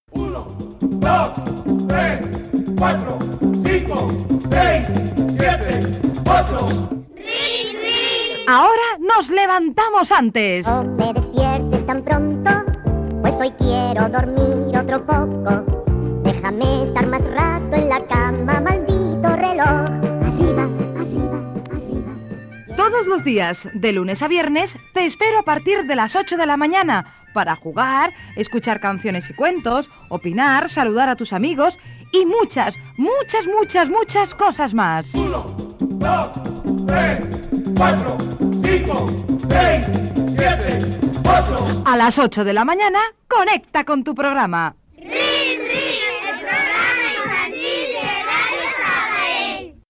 Promoció del programa
Infantil-juvenil